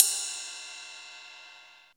RIDE CUP.wav